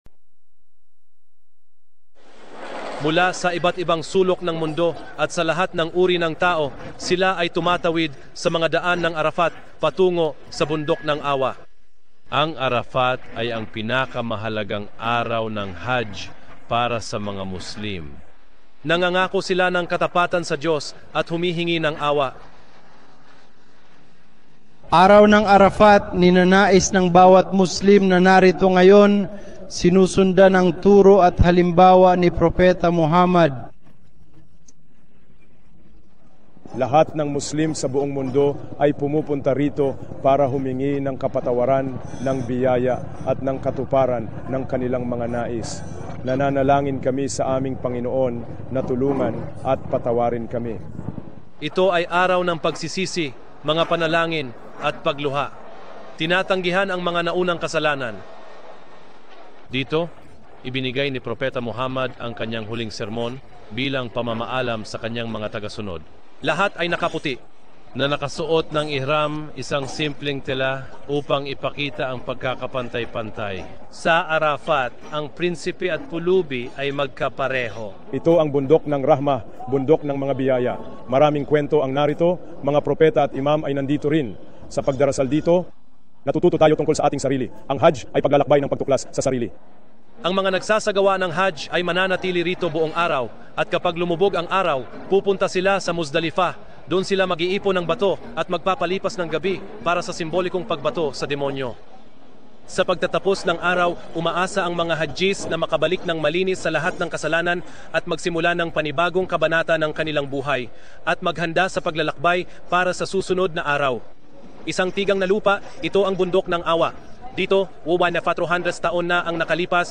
Paglalarawanˇ: Ang video na ito ay balita mula sa Al‑Jazeera tungkol sa kahalagahan ng Hajj.